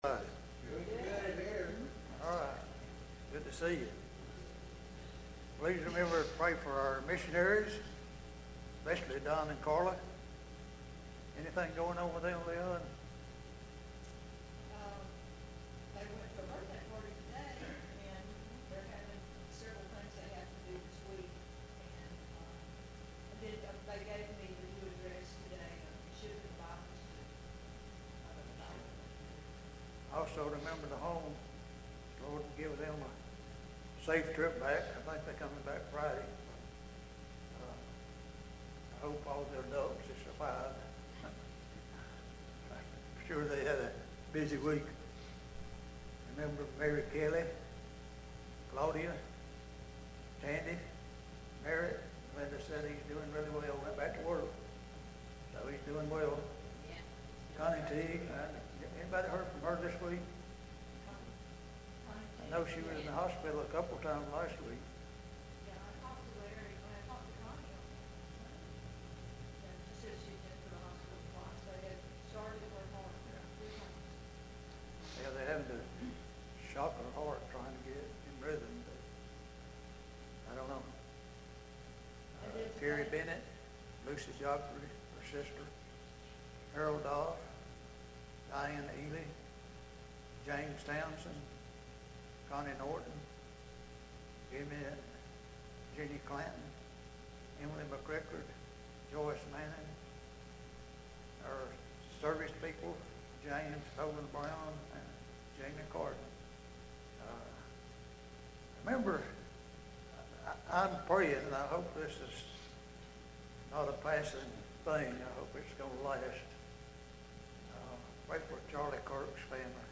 Wednesday Night Service